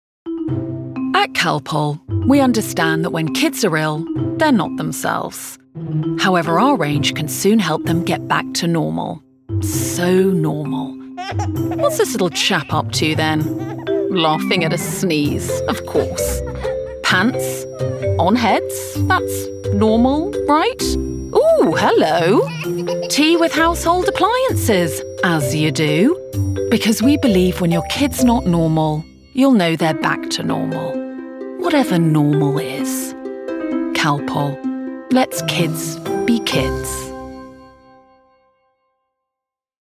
RP
Female
Assured
Bright
Dry
CALPOL COMMERCIAL